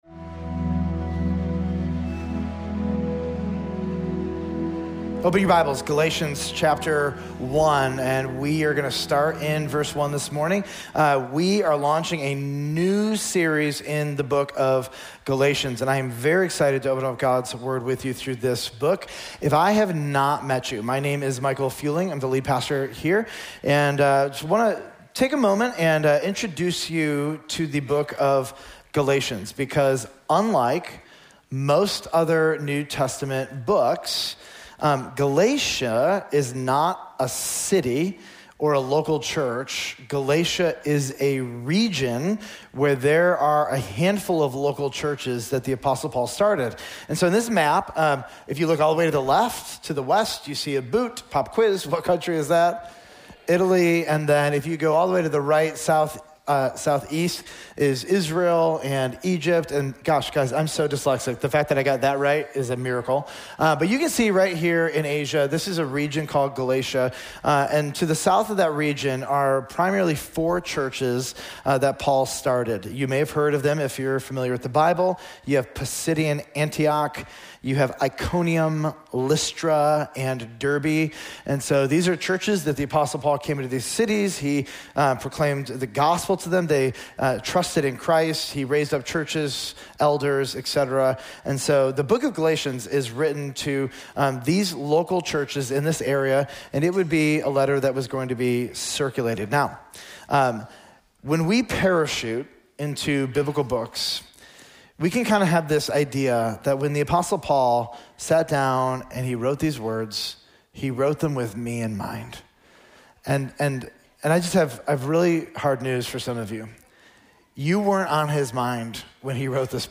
Village Church of Bartlett: Sermons Galatians Pt 1: What If I Reject The Gospel?